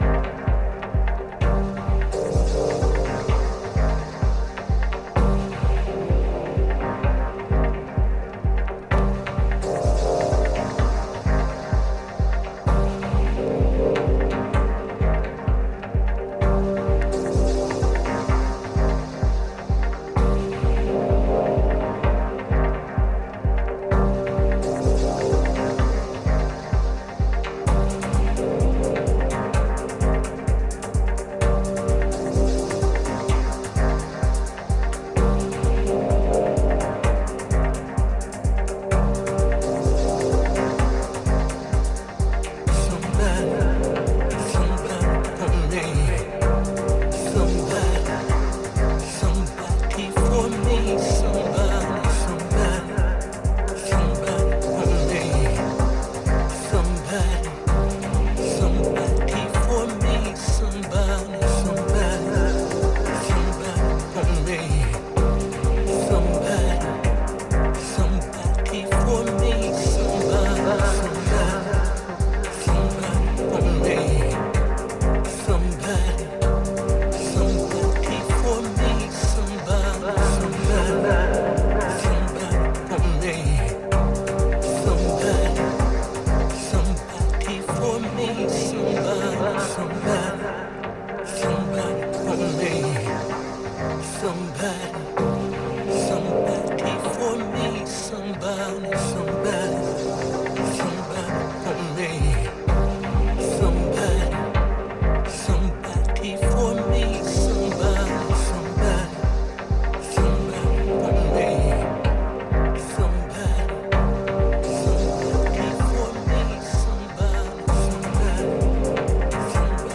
Dub Techno Techno